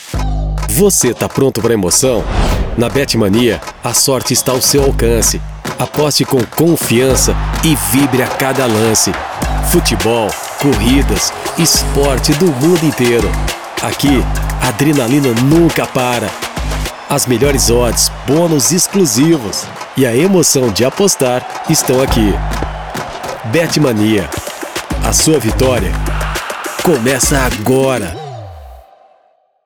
Natuurlijk, Vriendelijk, Zakelijk, Commercieel, Veelzijdig
Commercieel